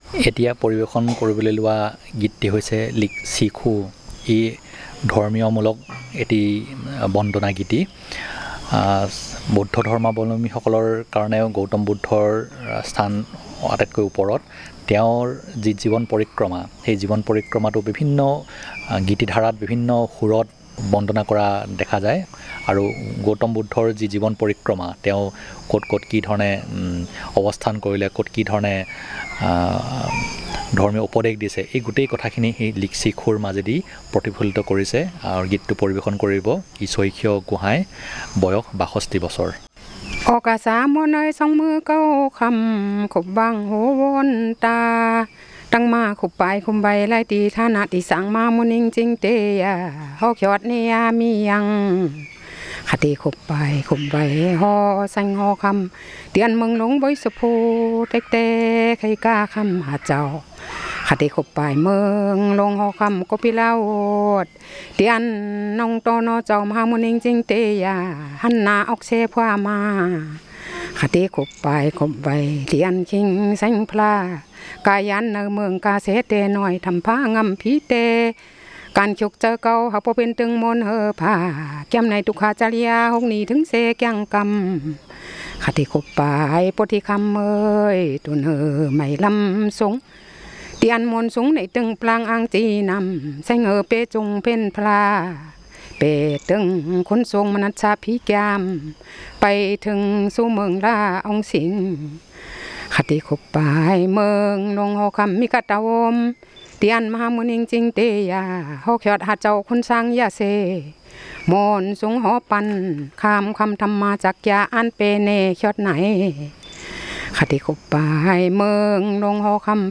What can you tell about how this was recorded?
Performance of a song about prayer in Buddhism